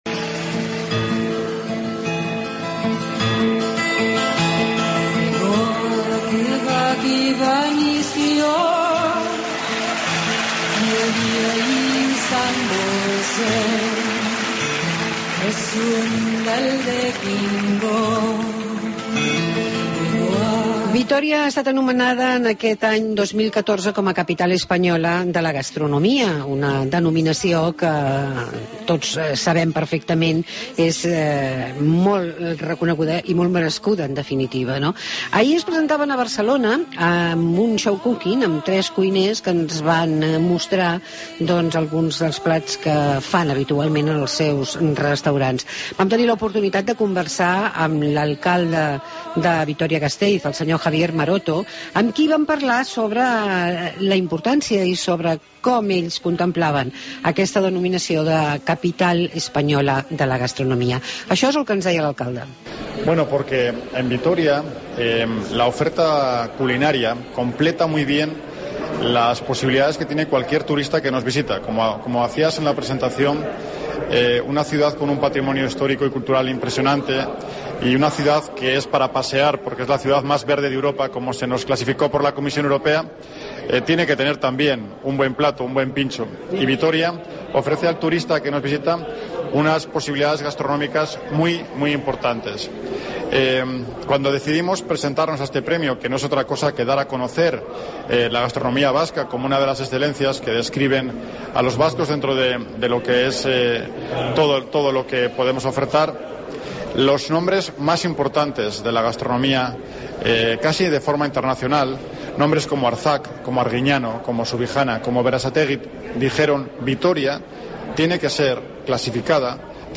Hablamos de la presentación de Vitoria-Gasteiz como capital gastronómica del 2014 con Javier Maroto, alcalde de la ciudad.